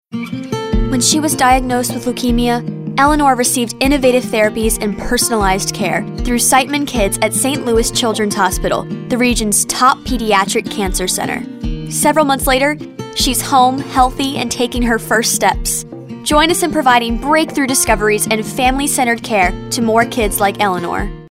announcer, caring, compelling, concerned, confident, genuine, teenager, thoughtful, warm, young adult